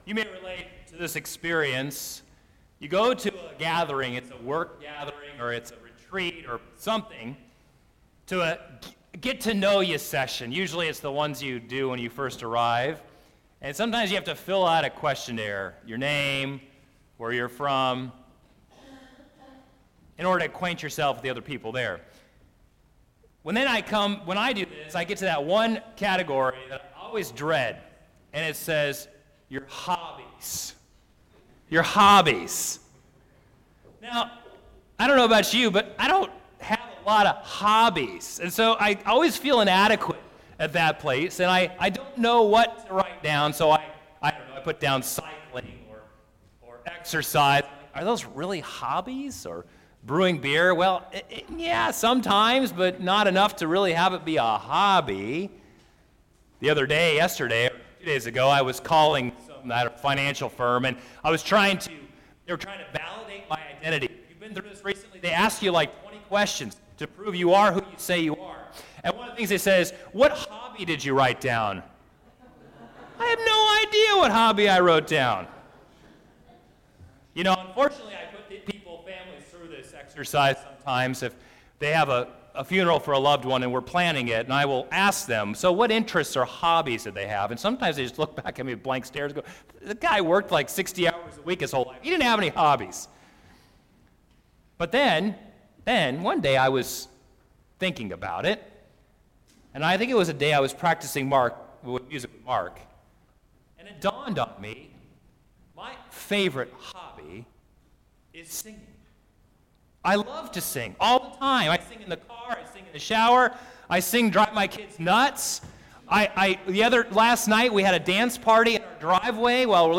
Sermon-9.10.17.mp3